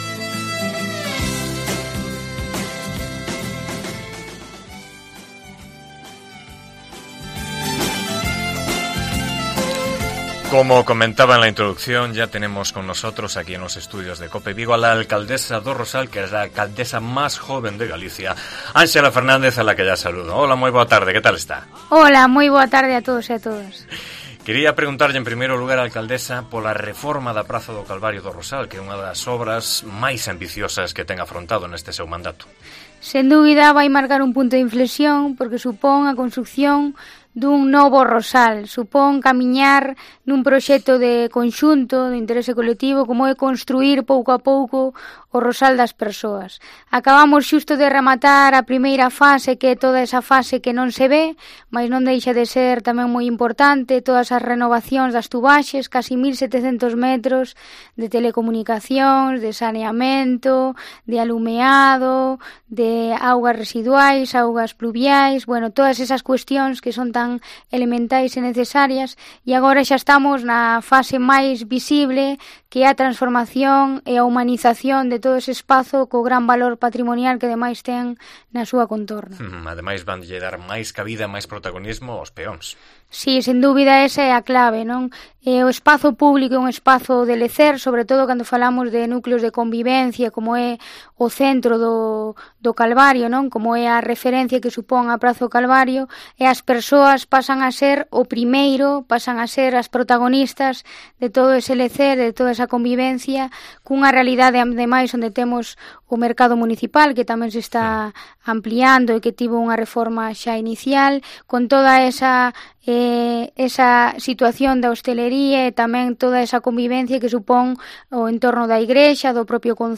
Entrevistamos a Ánxela Fernández, alcaldesa do Rosal